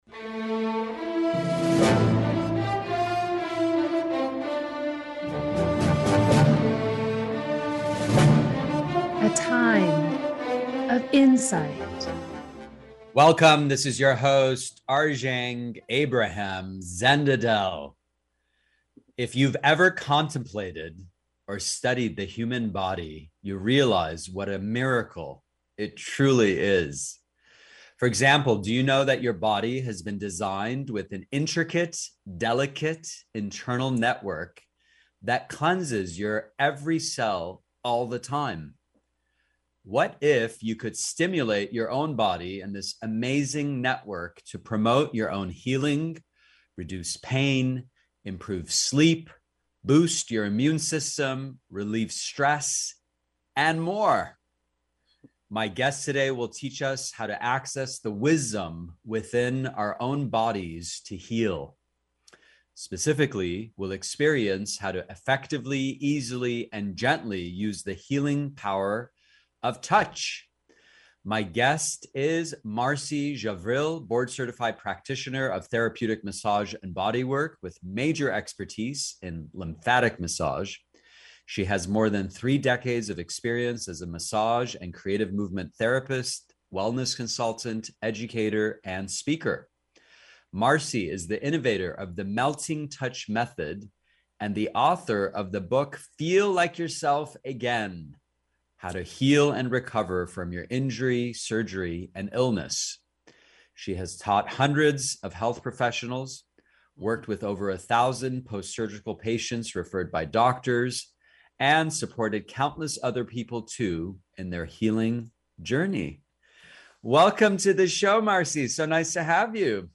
Podcast Interview – 6/20/22